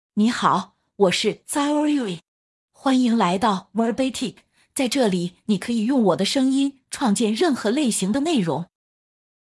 Xiaorui — Female Chinese (Mandarin, Simplified) AI Voice | TTS, Voice Cloning & Video | Verbatik AI
Xiaorui is a female AI voice for Chinese (Mandarin, Simplified).
Voice sample
Listen to Xiaorui's female Chinese voice.
Female
Xiaorui delivers clear pronunciation with authentic Mandarin, Simplified Chinese intonation, making your content sound professionally produced.